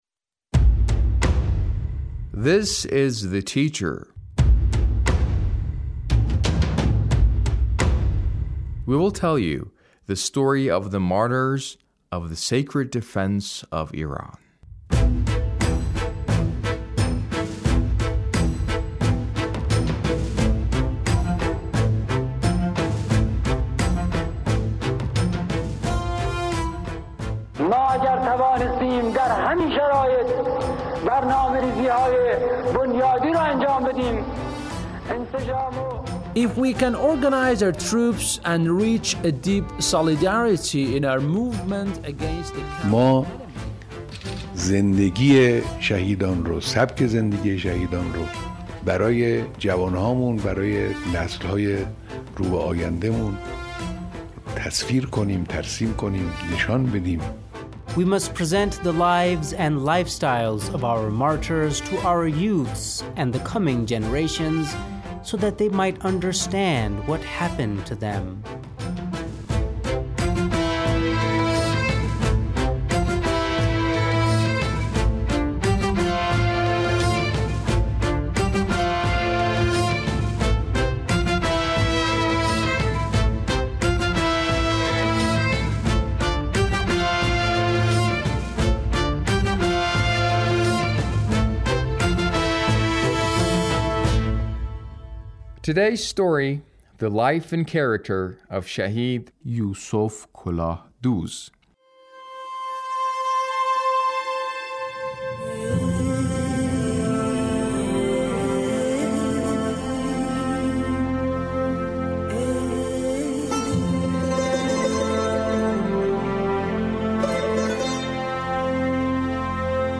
A radio documentary on the life of Shahid Yusof Kolahdooz- Part 1